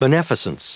Prononciation du mot beneficence en anglais (fichier audio)
Prononciation du mot : beneficence